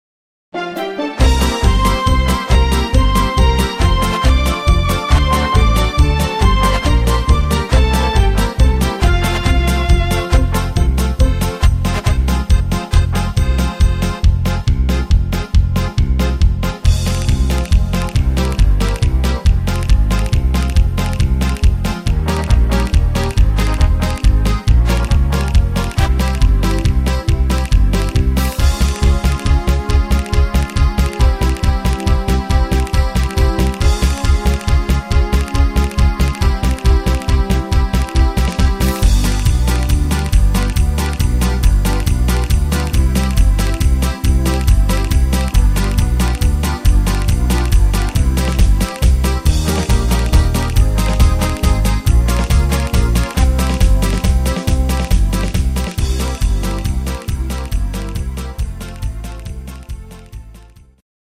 instr. Paso Doble